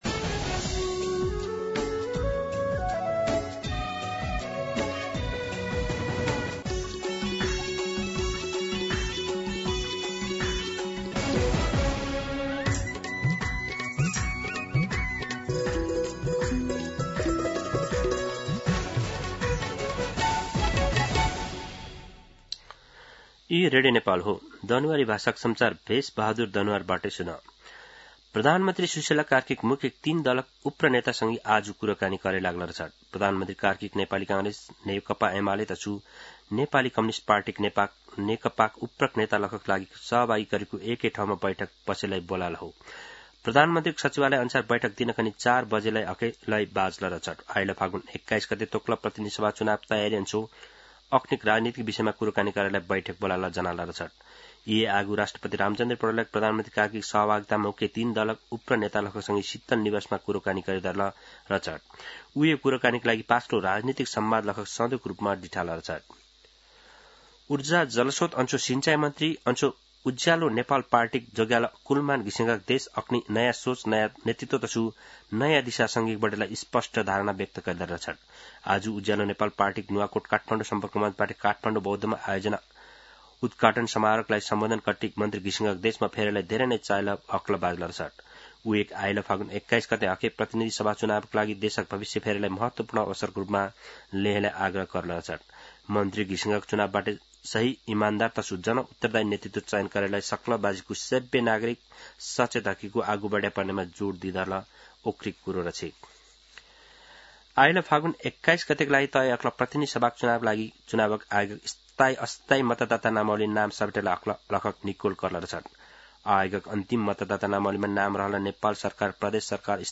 दनुवार भाषामा समाचार : १२ पुष , २०८२
Danuwar-News-1.mp3